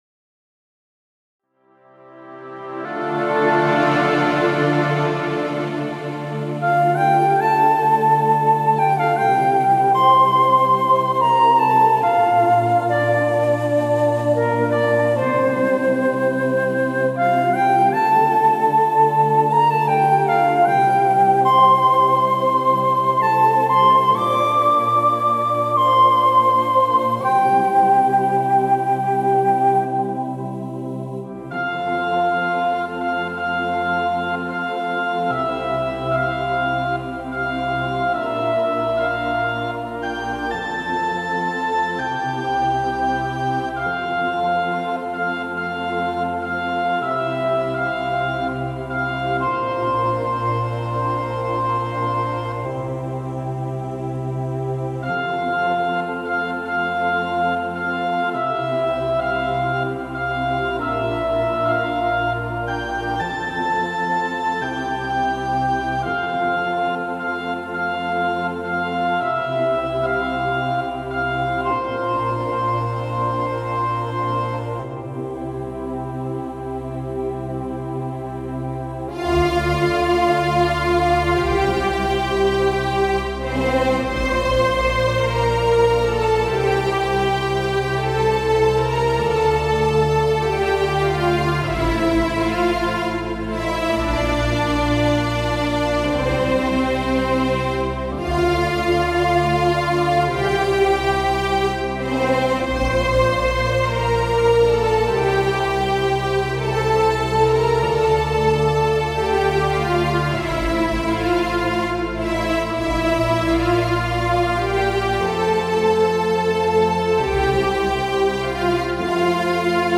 Bravo pour cette reprise avec ces chœurs